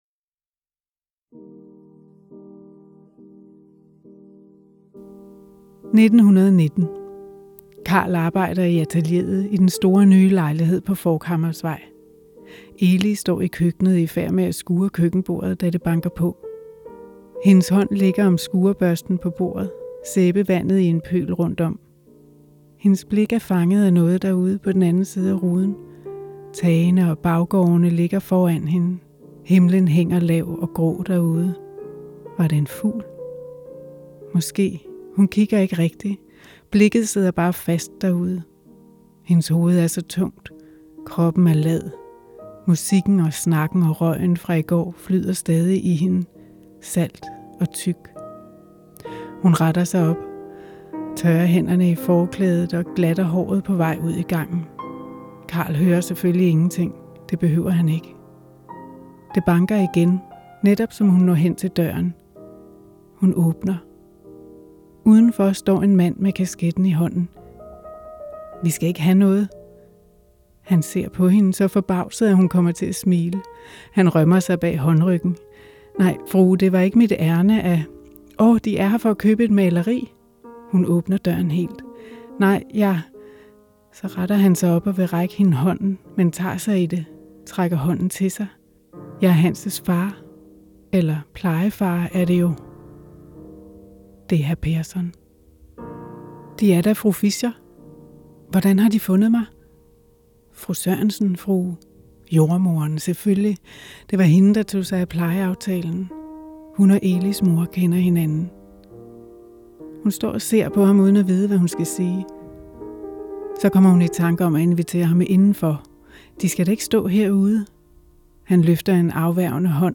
læse et uddrag, der foregår på Forchhammersvej 7.